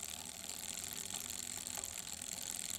effect__bike_chain.wav